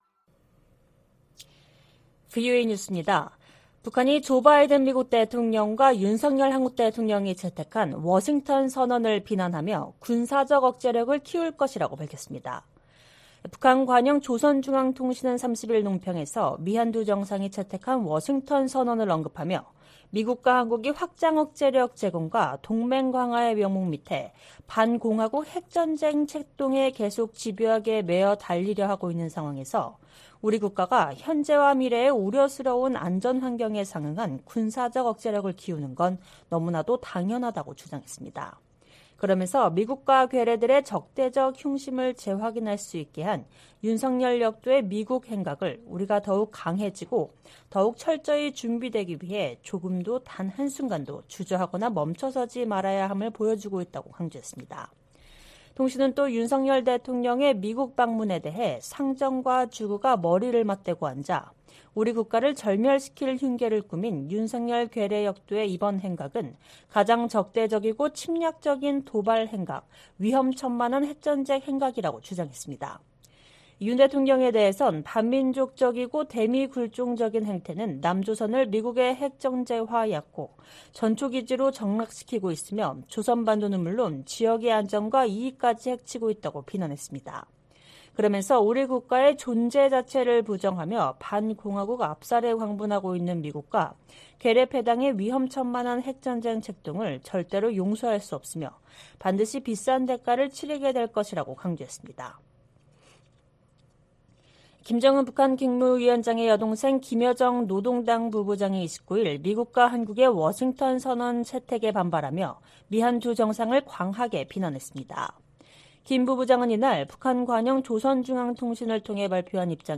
VOA 한국어 방송의 일요일 오후 프로그램 4부입니다. 한반도 시간 오후 11:00 부터 자정 까지 방송됩니다.